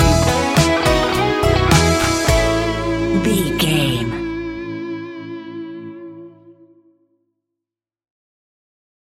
Ionian/Major
ambient
electronic
new age
chill out
downtempo
synth
pads